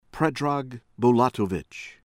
BULATOVIC, PREDRAG PREH-drahg    boo-LAH-toh-vihch